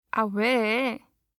알림음 8_아왜1-여자.mp3